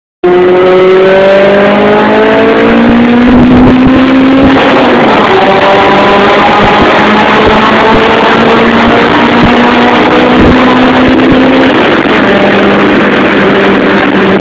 111db bei 4500rpm
Tunel.mp3